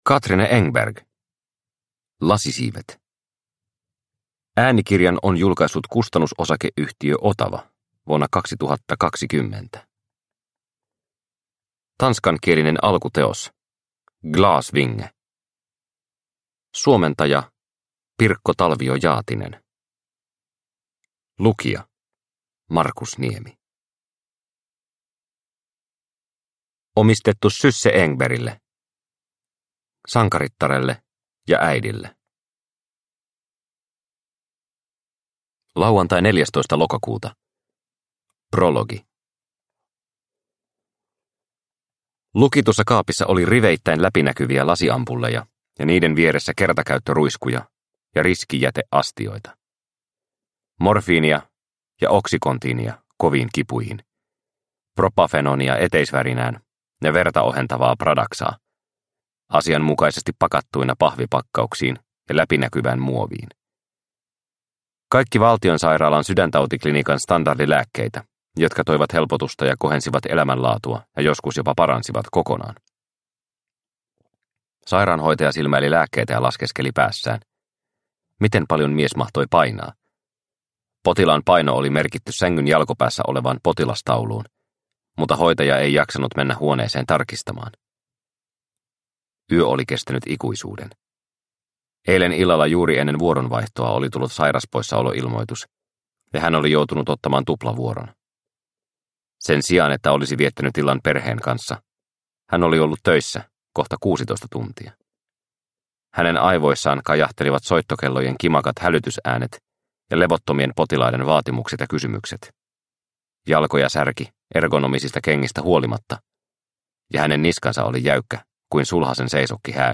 Lasisiivet – Ljudbok – Laddas ner